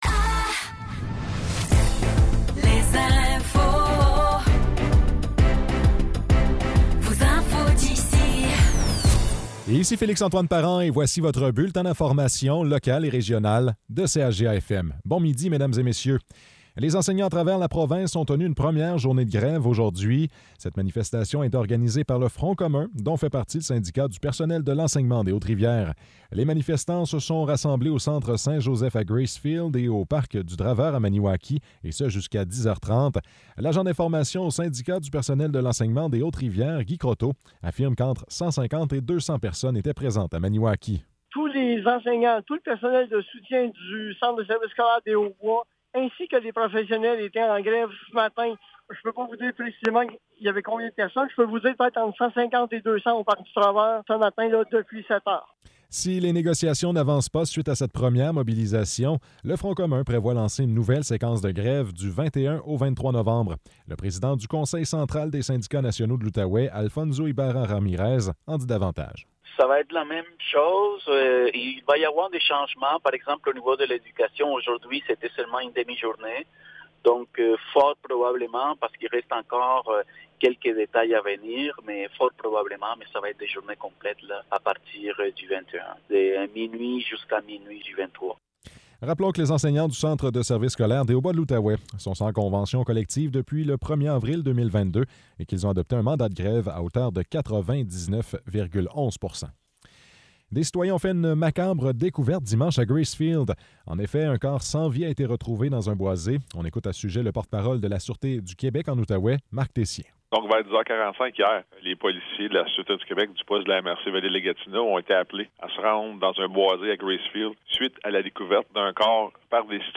Nouvelles locales - 6 novembre 2023 - 12 h